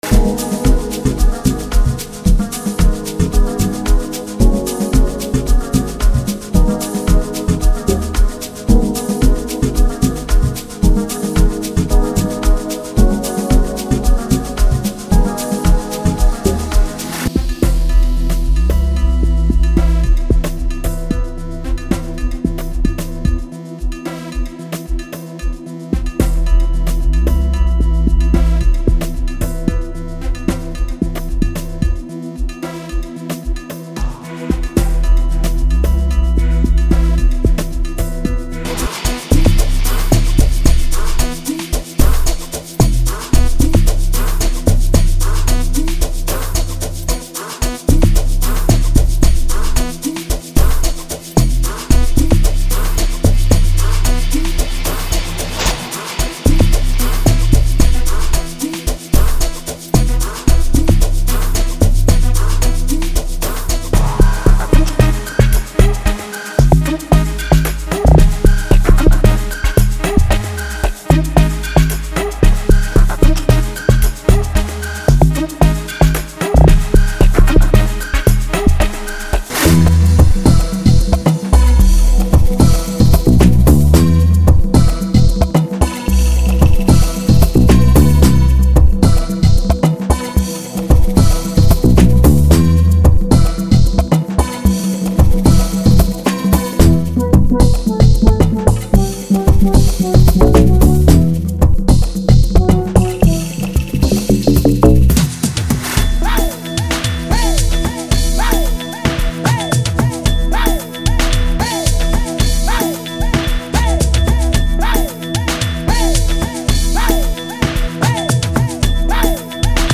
• Including Drums, Melodics, Vocals, and FXs.
• Including Drums, Percussions, Voxes, and FXs.